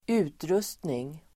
Uttal: [²'u:trus:tning]